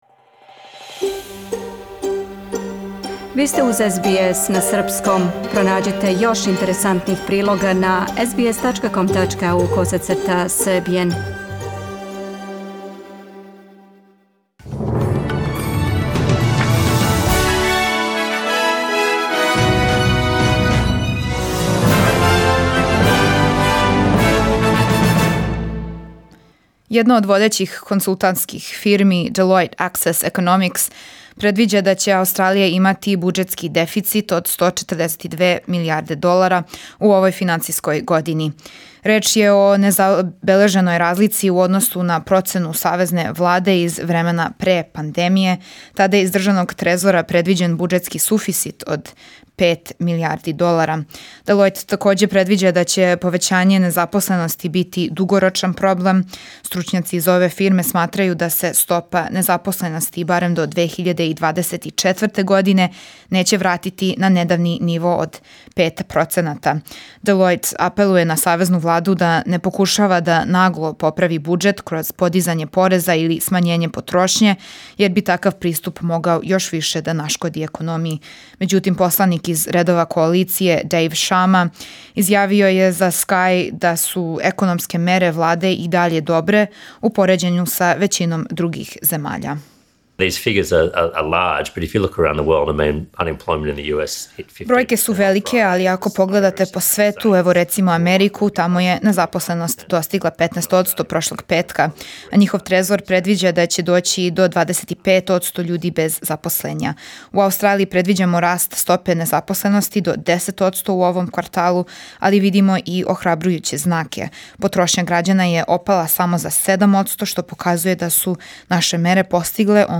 Сазнајте најважније новости из Аустралије, Србије и света у дневном прегледу вести СБС радија на српском језику за понедељак, 11. мај 2020. године.